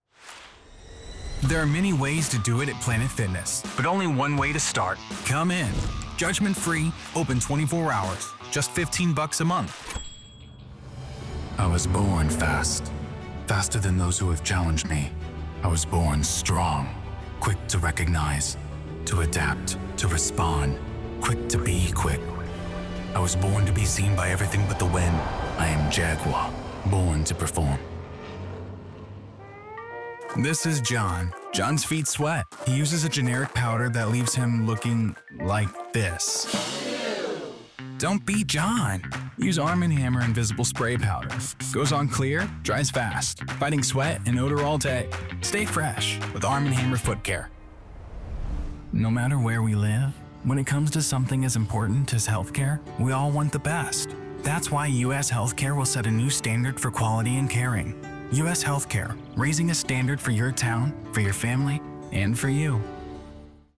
In commercial work, they move easily from calm and conversational to confident and convincing. Their voice can be warm, gritty, or quietly authoritative always connecting without overselling.
COMMERCIAL 💸
broadcast level home studio